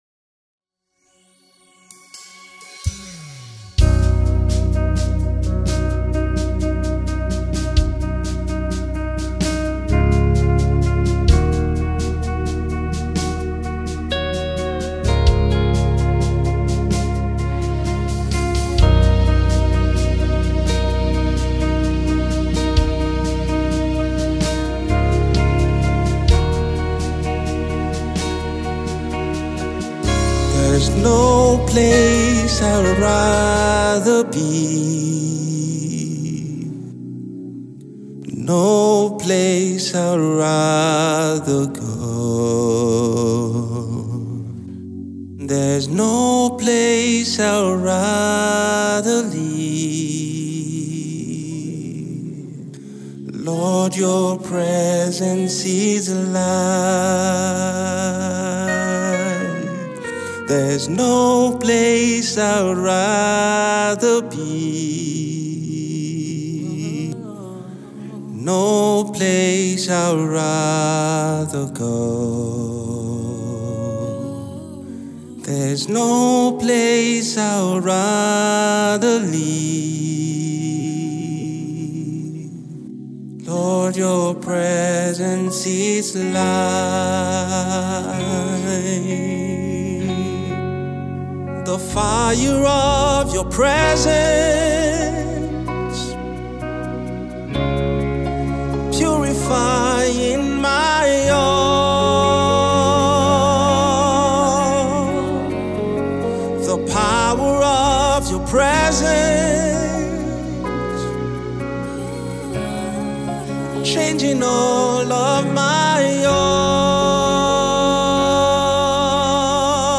Bgvs